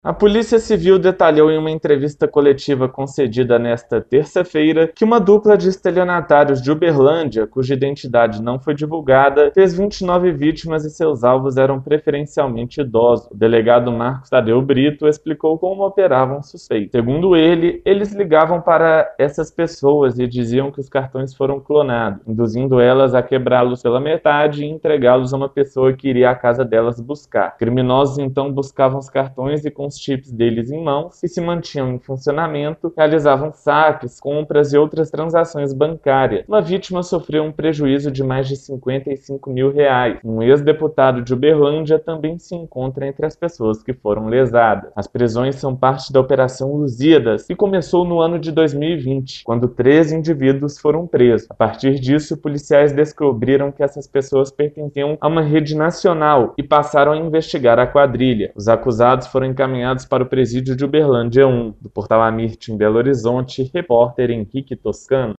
A Polícia Civil (PC) detalhou em uma entrevista coletiva concedida nesta terça-feira (14), que uma dupla de estelionatários de Uberlândia, cuja identidade não foi divulgada, fez 29 vítimas e seus alvos eram preferencialmente idosos.